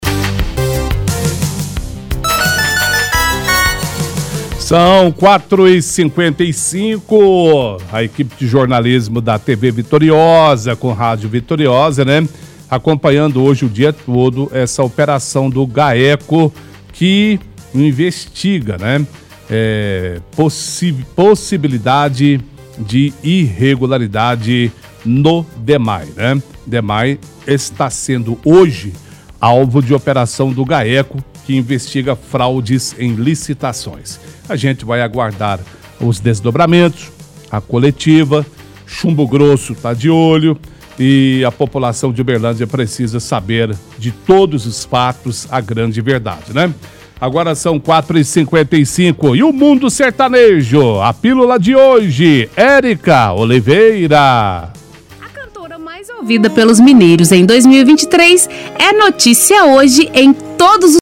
-Apresentador comenta que o Gaeco está investigando a possibilidade de irregularidades e fraudes no Dmae.